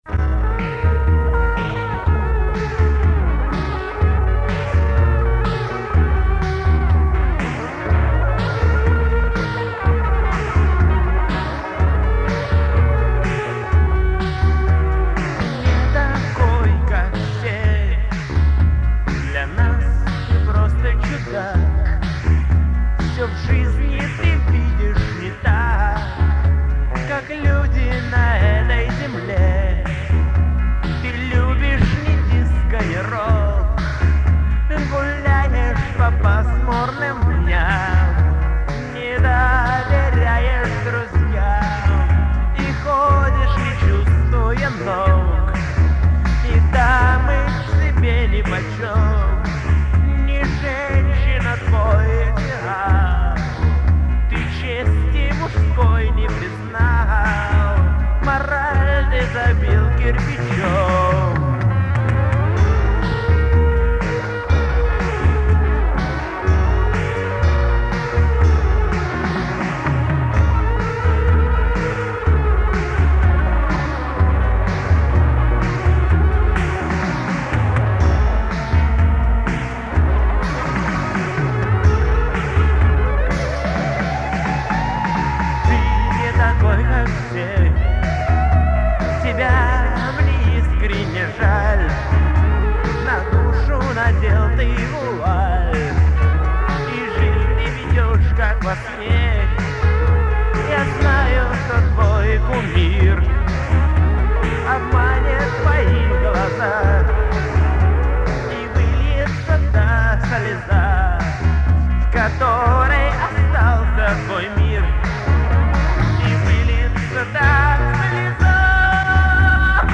Сборник пробных записей, этюдов, репетиций
музыка, текст, вокал, гитары
барабаны, перкуссия, драм-машина
бэк-вокал, бас-гитара
клавиши (фортепиано, vermona, электроника)